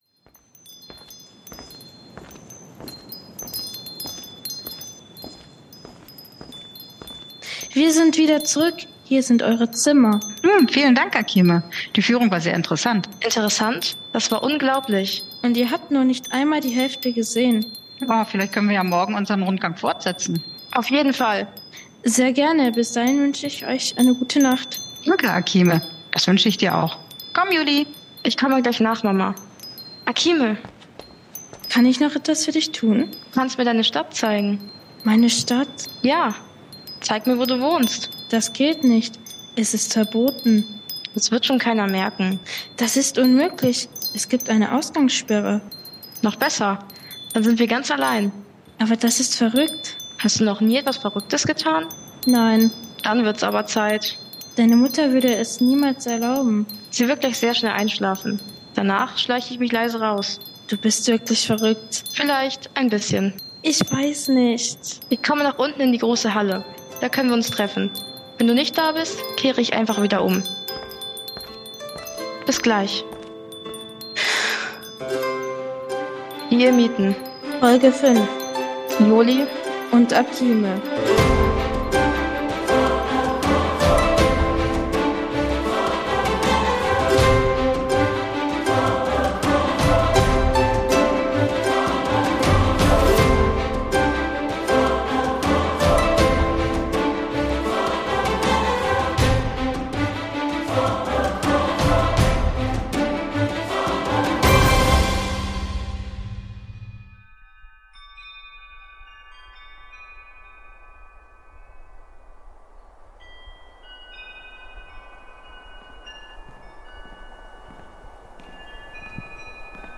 Ein Science-Fiction Hörspiel in 5 Staffeln. Staffel 01 Begegnungen Dieses Hörspiel ist ein reines Hobby Projekt ohne Anspruch auf Perfektion.